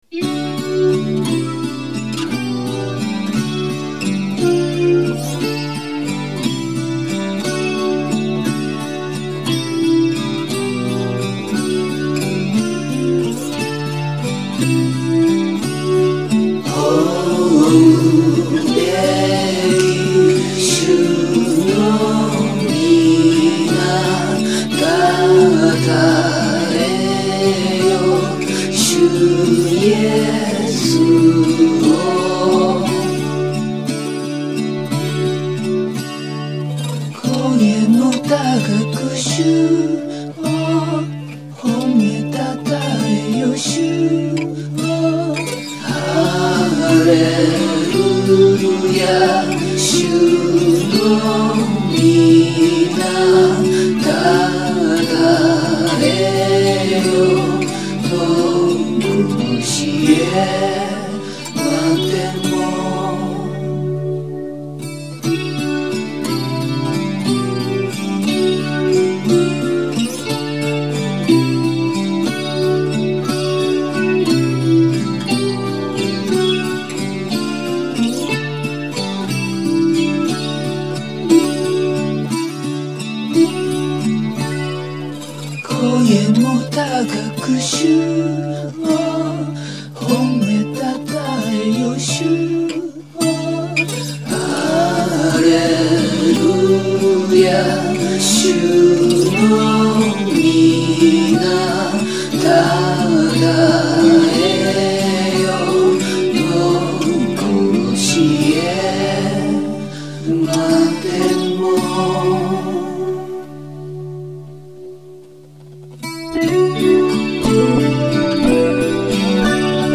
1988年に作曲したオリジナルの賛美曲。プロギーでアンビエントかつブルージー。